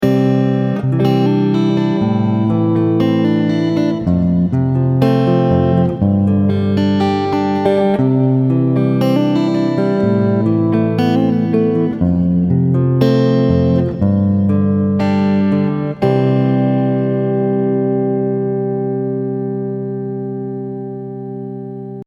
In the second example, I play the exact same progression, but this time with the C major chord at the end to finish it off.
C, Am, F, G, C
Sounds kind of like the end, right?